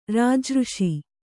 ♪ rāja řṣi